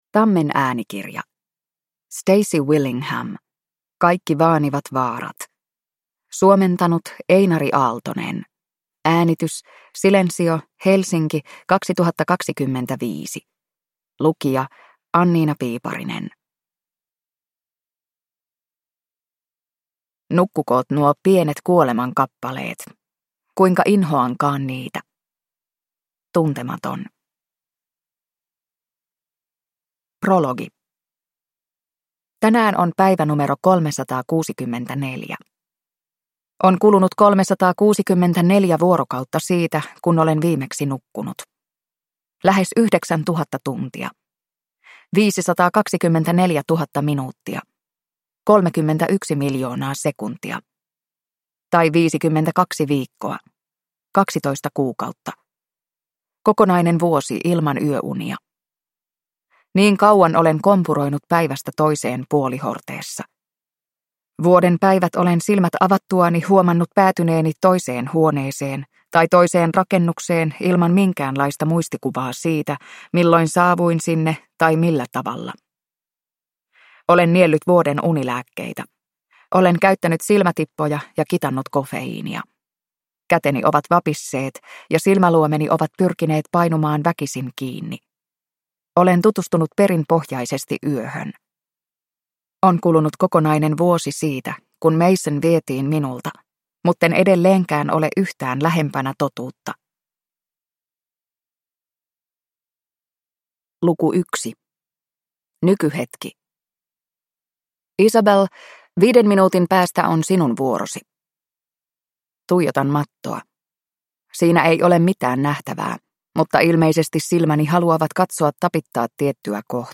Kaikki vaanivat vaarat (ljudbok) av Stacy Willingham